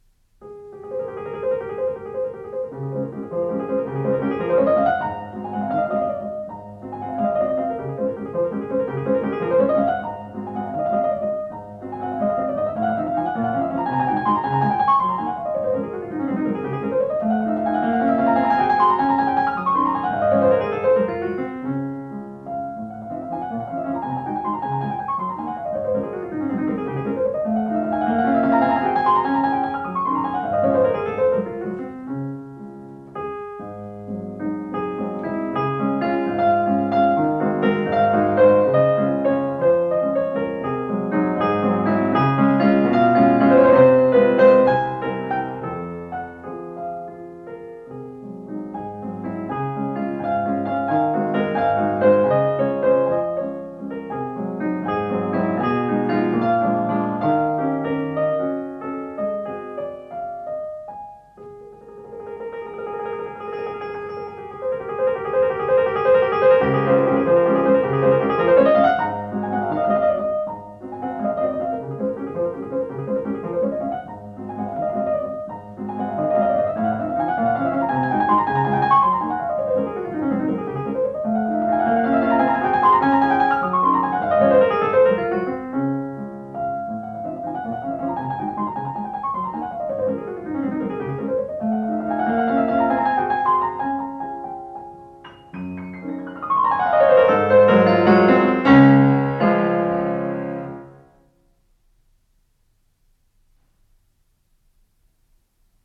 罗马尼亚钢琴家，其父是萨拉萨蒂的学生，其母是钢琴家，其教父是埃乃斯库。